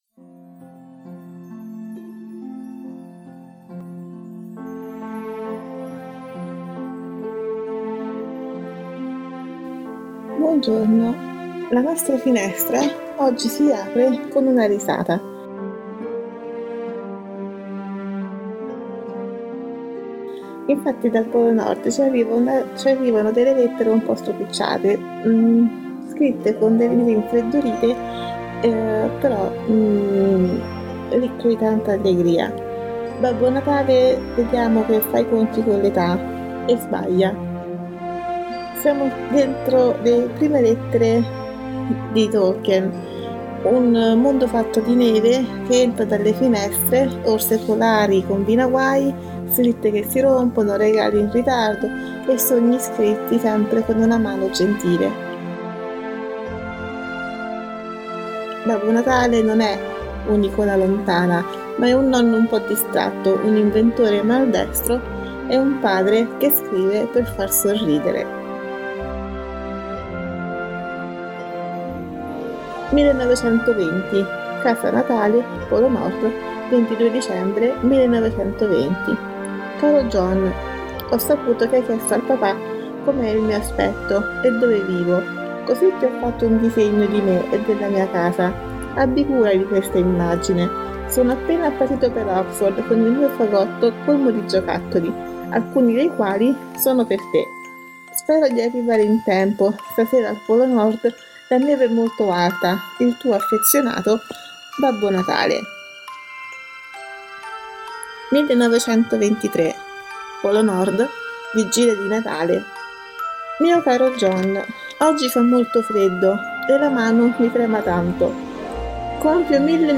La finestra del 2 dicembre si apre con una risata.